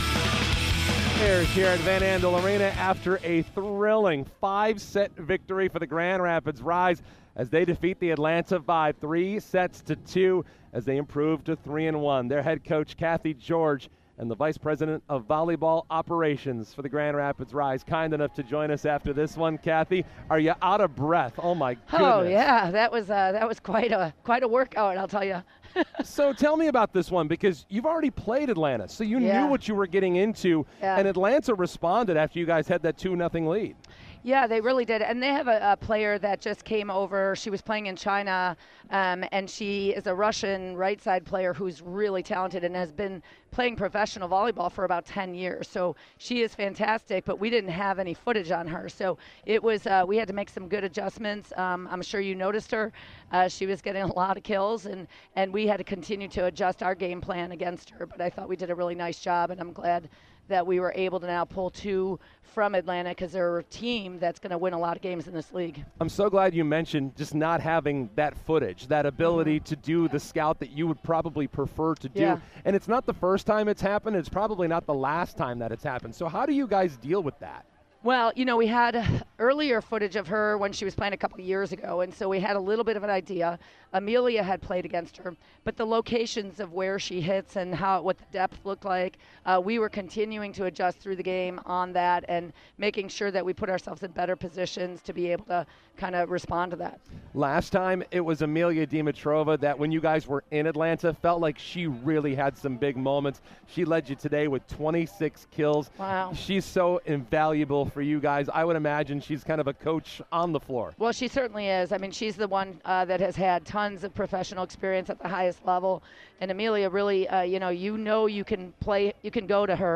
Post-Match Interviews